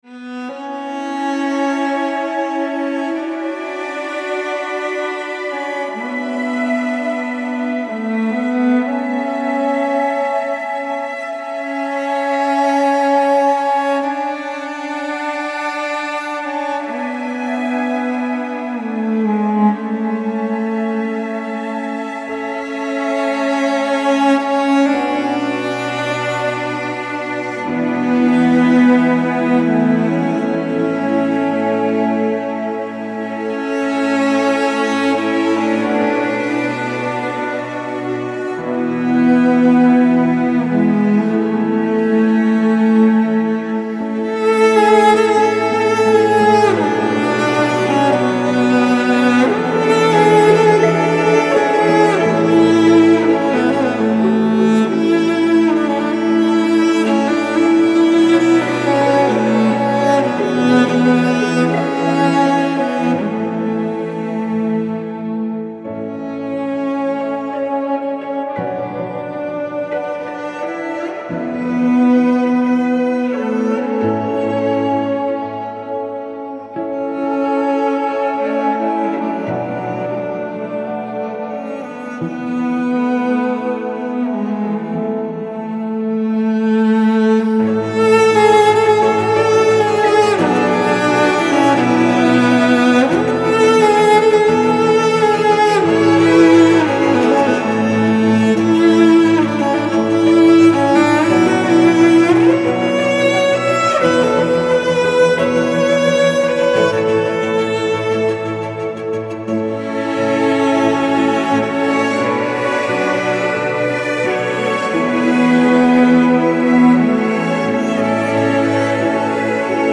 آرامش بخش
Classical Crossover